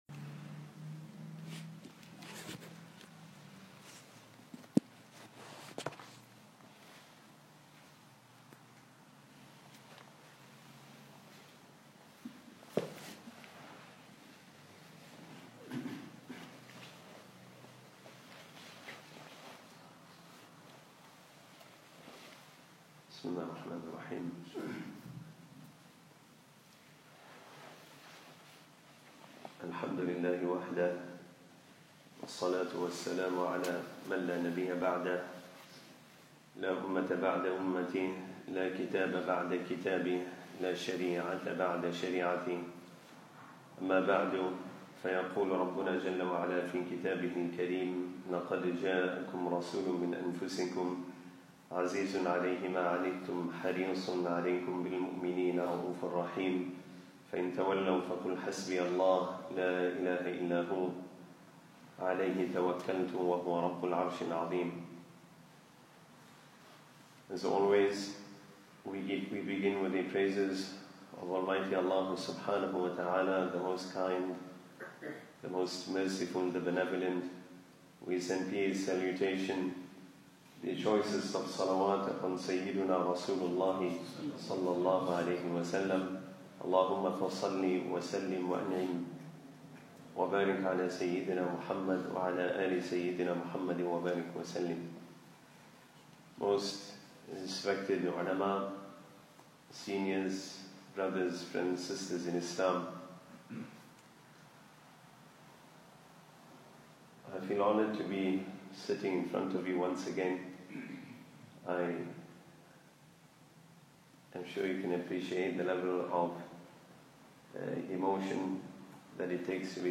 Monday Tathkirah Class Audio Playlist
Weekly Lectures